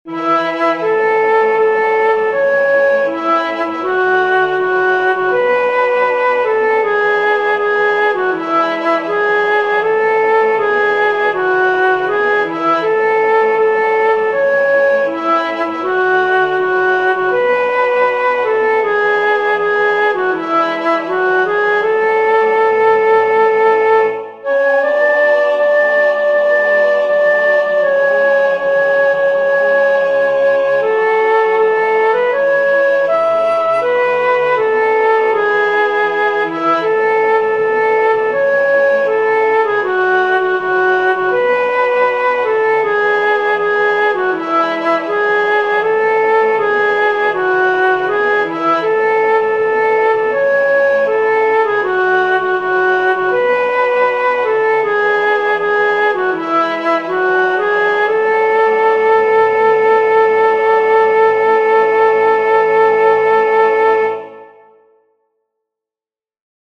Esta canción popular tiene una estructura muy sencilla, tipo A-B-A’.
El tempo aparece indicado como Moderato.
o-sari-mares-soprano2.mp3